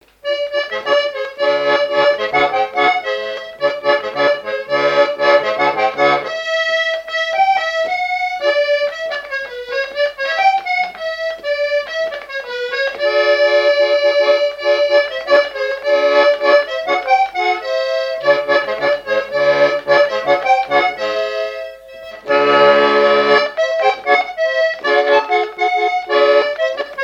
Genre énumérative
Répertoire et souvenir des musiciens locaux
Pièce musicale inédite